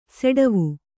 ♪ seḍavu